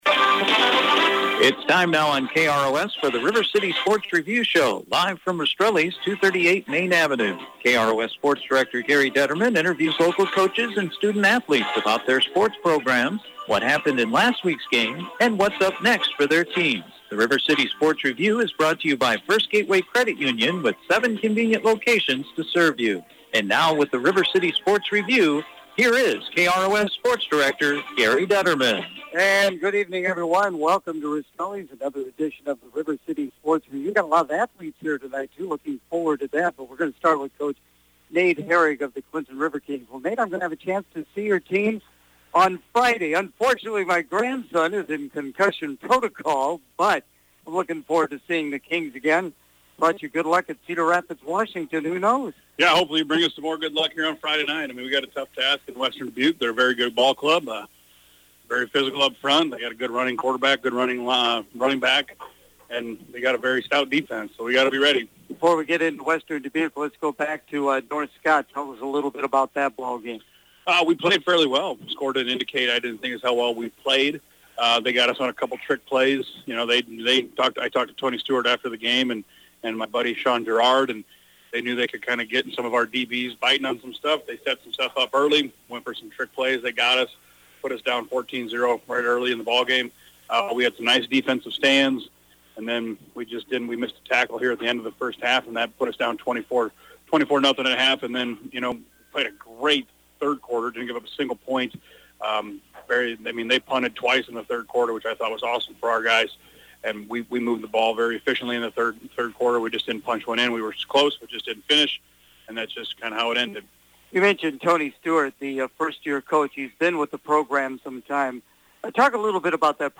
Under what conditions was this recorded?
from Rastrelli’s Restaurant